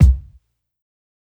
KICK_FPROG.wav